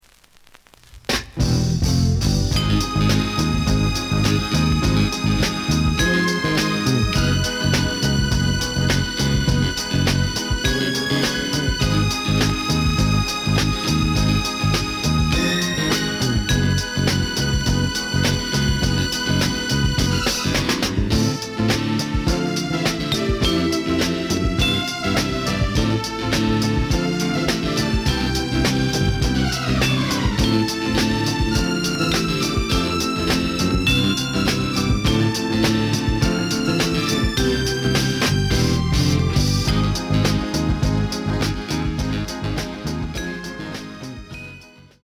The listen sample is recorded from the actual item.
●Format: 7 inch
●Genre: Soul, 70's Soul